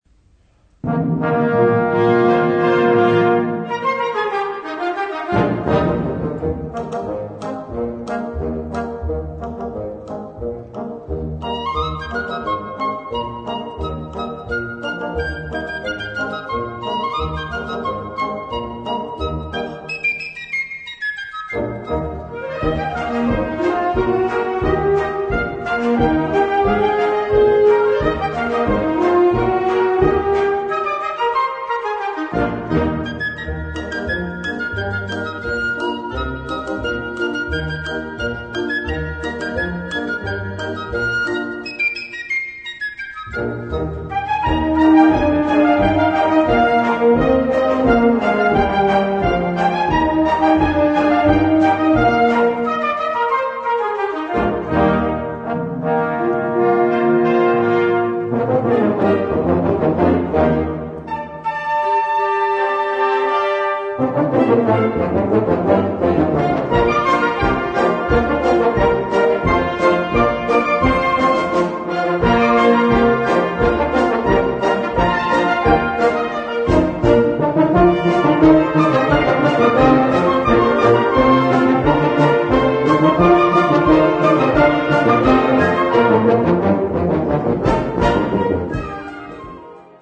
Besetzung Ha (Blasorchester)